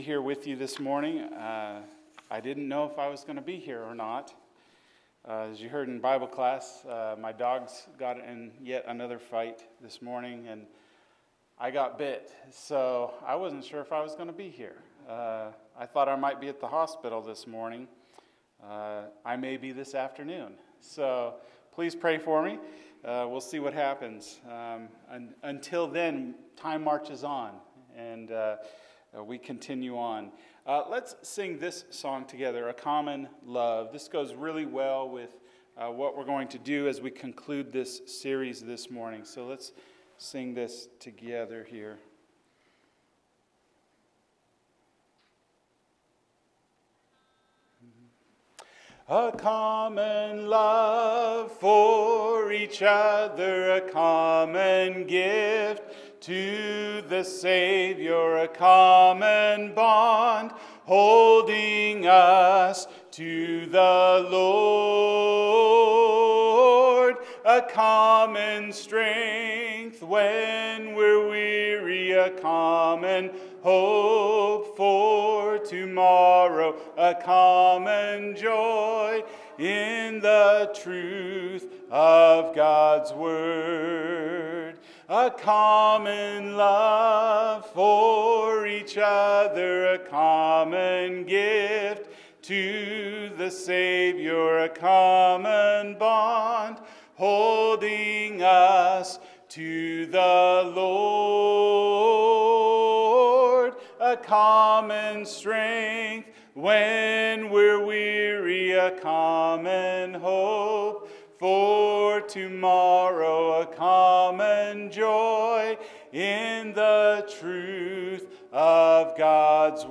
What Unifies Us? – Ephesians 4 – Sermon